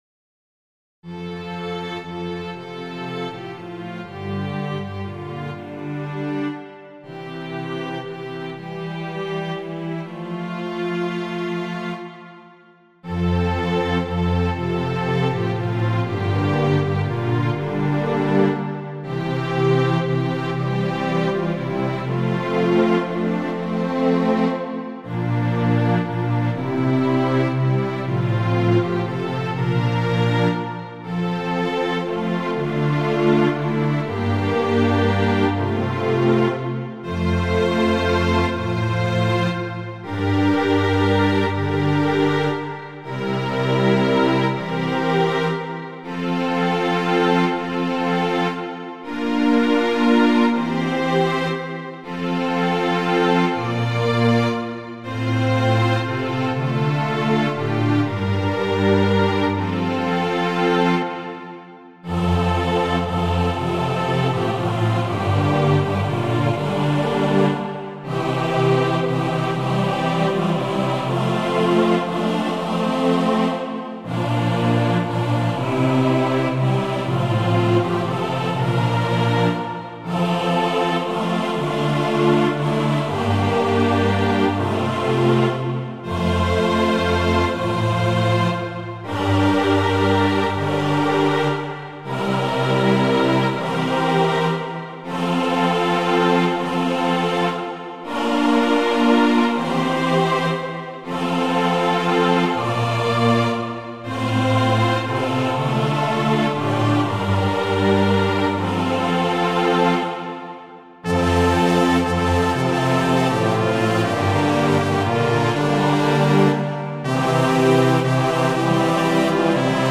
[MP3 - Choir Arrangement]
(A Two Part Round)